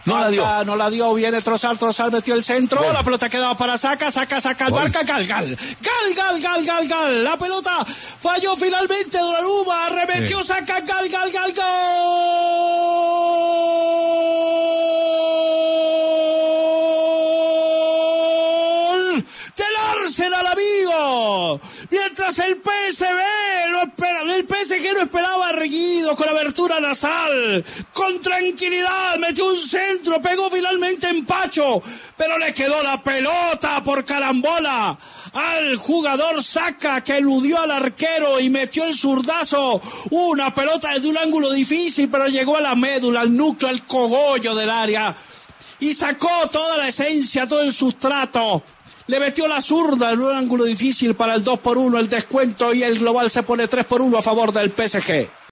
Así narró el gol del Arsenal Martín de Francisco: